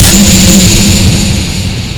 sonarTailWaterVeryClose3.ogg